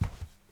krok_04.wav